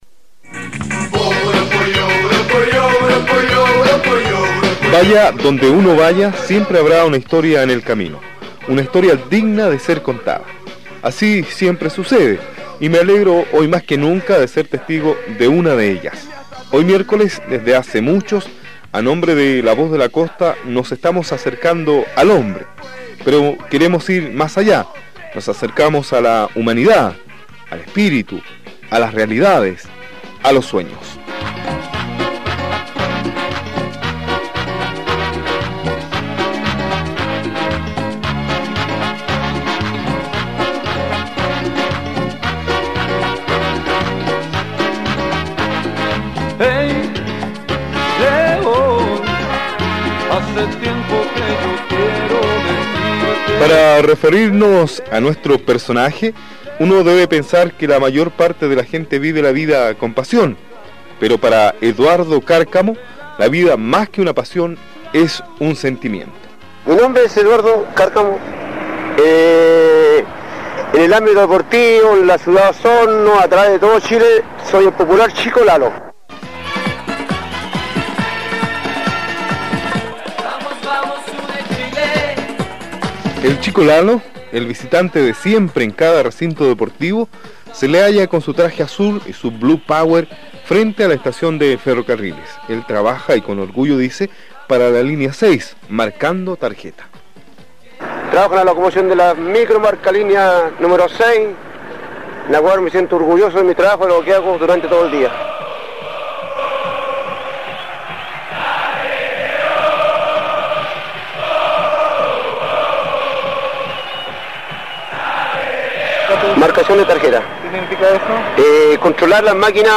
Reportaje Radial